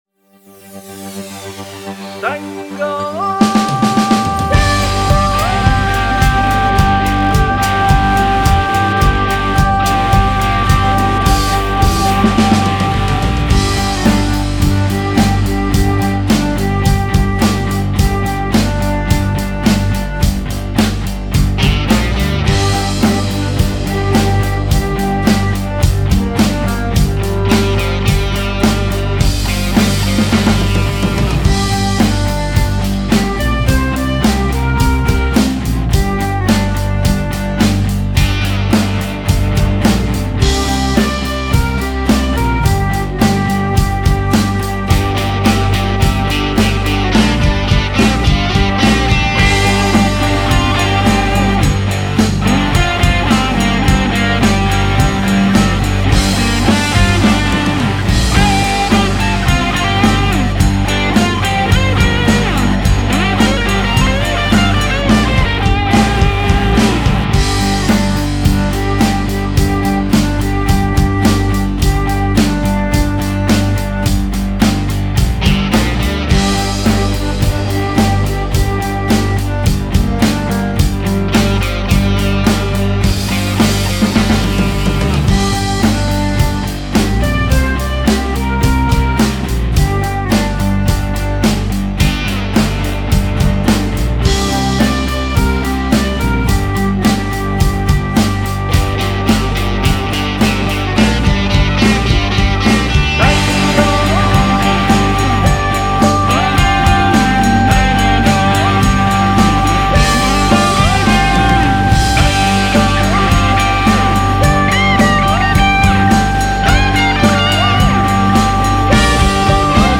客家山歌子曲調《少年讀書愛用心》 - 新北市客家數位館
少年讀書愛用心 示範帶.mp3(另開新視窗)